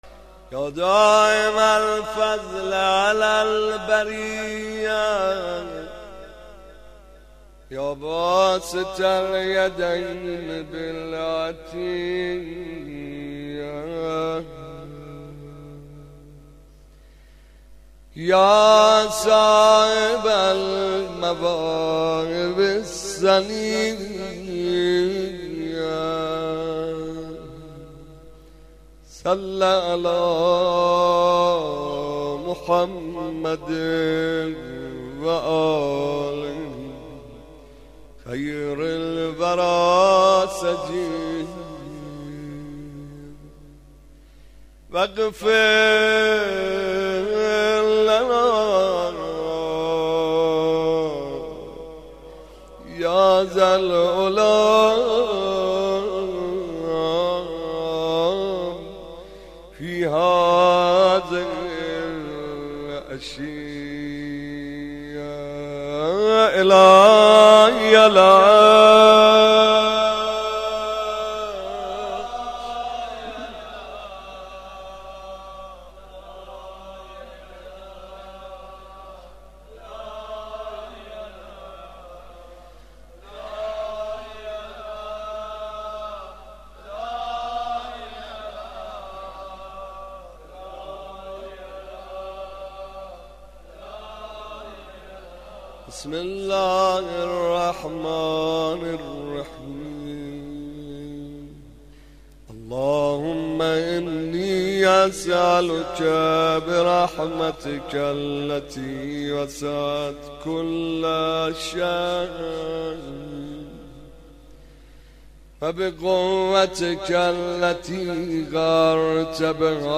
روضه و ذکر
komeil vafat hazrat omolbanin 02.mp3